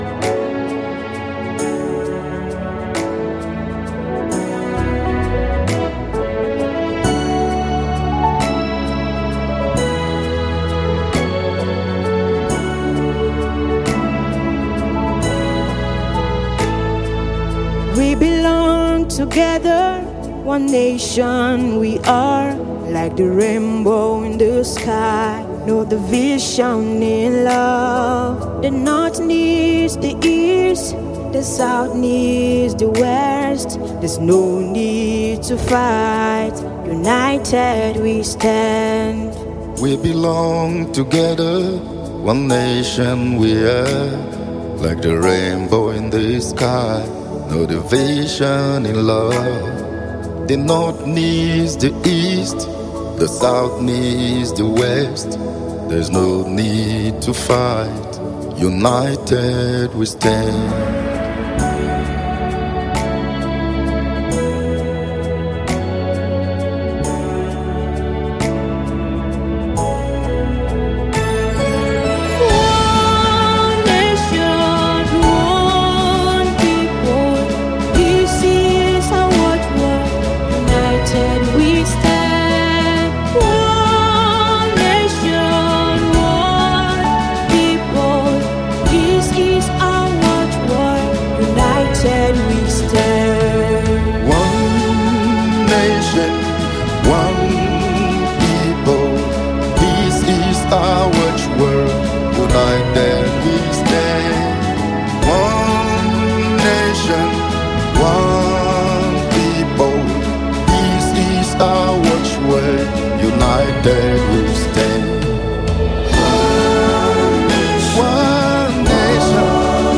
With its catchy melody and inspiring words
adding depth and richness to the track.